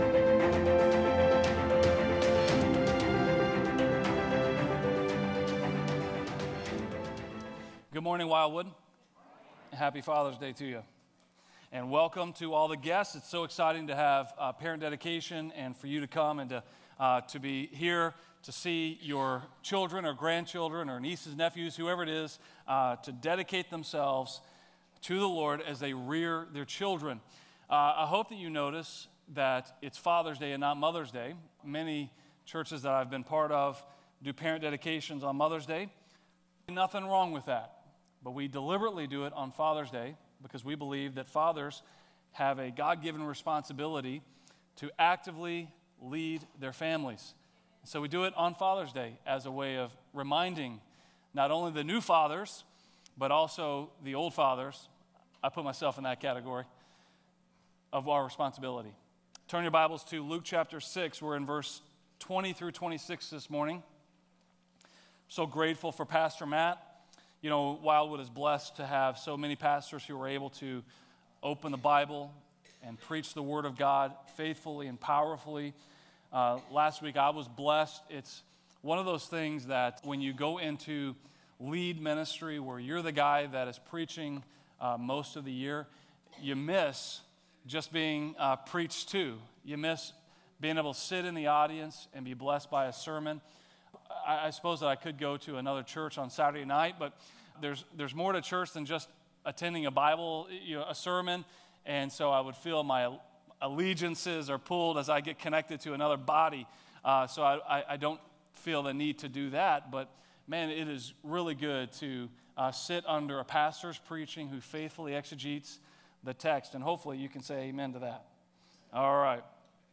Message
A message from the series "Wisdom From Above."